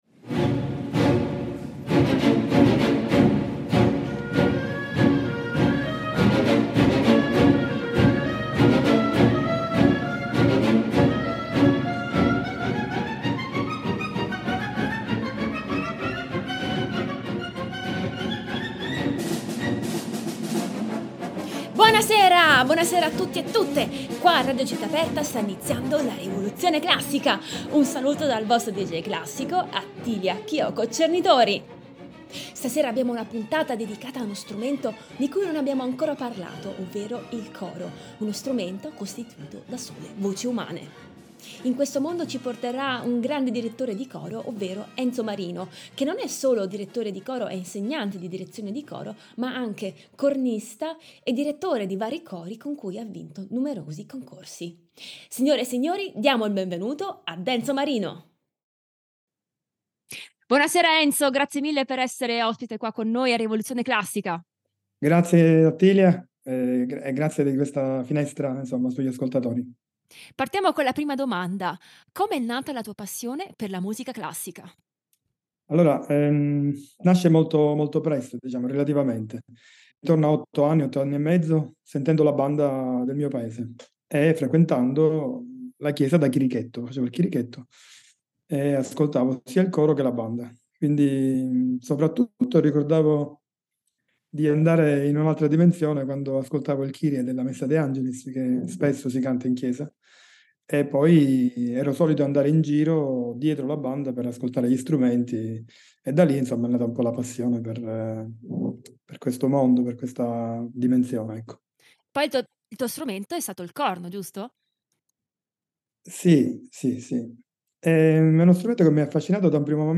Gruppo vocale
Orchestra Sinfonica Siciliana